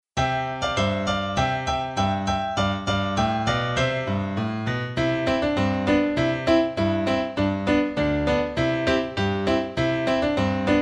Fast
Nursery Rhyme